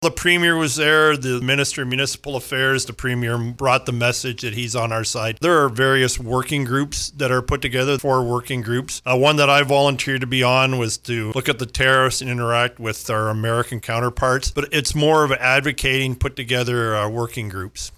Strathroy-Caradoc Mayor Colin Grantham tells us more.